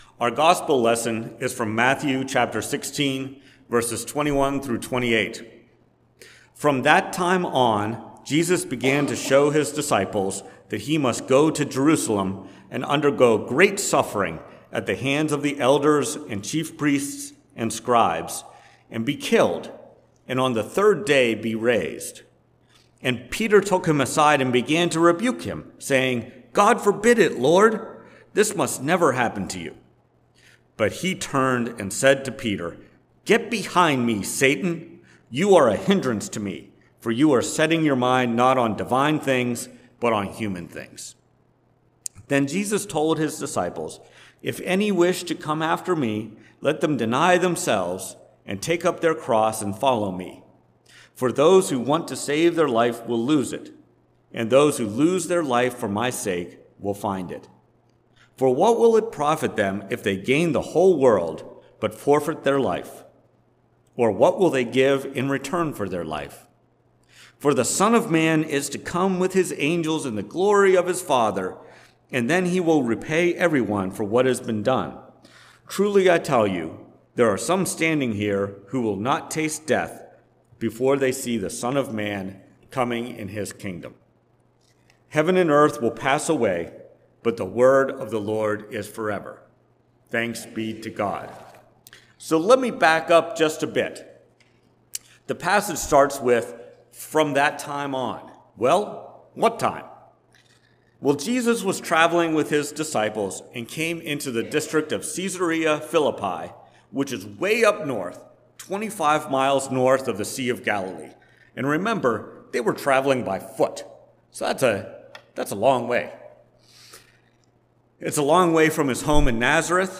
Sermon on September 3, 2023, at First Presbyterian Church of Rolla. Based on Matthew 16:21-28.